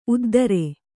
♪ uddare